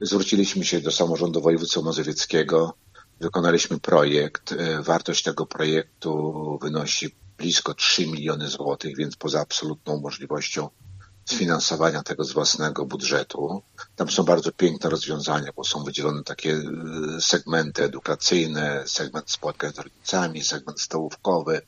Wójt Łukaszewski dodaje, że działania, które zostały podjęte dają efekty: